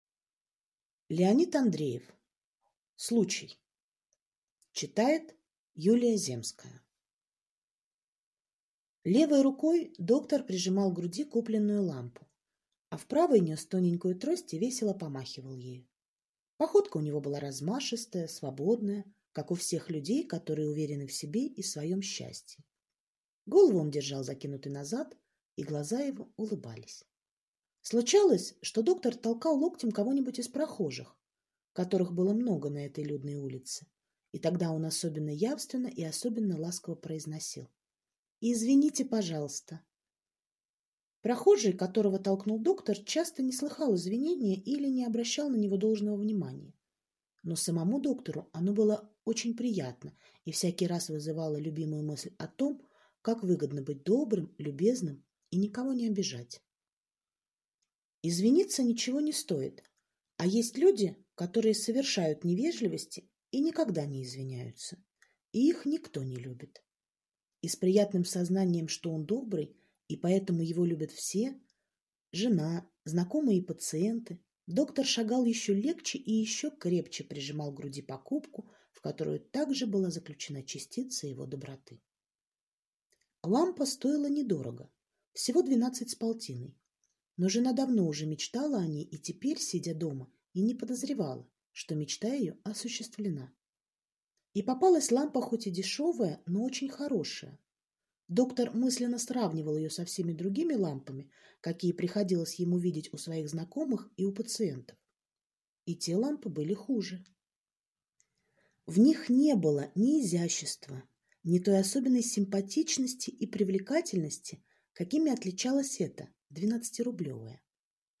Аудиокнига Случай | Библиотека аудиокниг